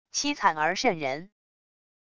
凄惨而渗人wav音频